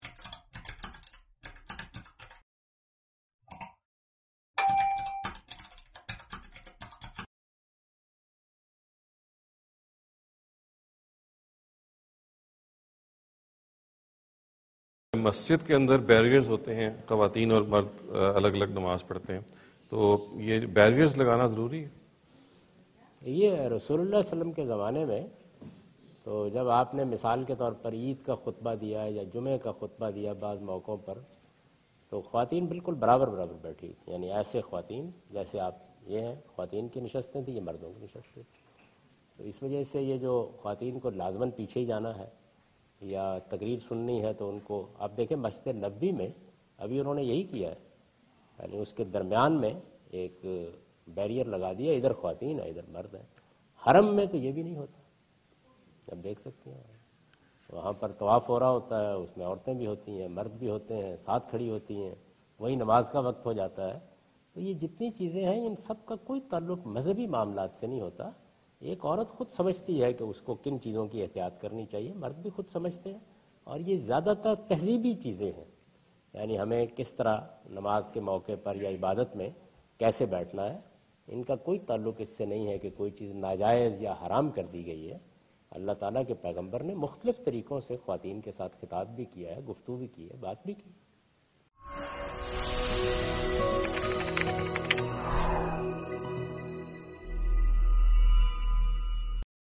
Javed Ahmad Ghamidi answer the question about "Placing Barriers in Mosques to Separate Women from Men" during his visit to Georgetown (Washington, D.C. USA) May 2015.
جاوید احمد غامدی اپنے دورہ امریکہ کے دوران جارج ٹاون میں "مساجد اور اختلاطِ مرد و زن" سے متعلق ایک سوال کا جواب دے رہے ہیں۔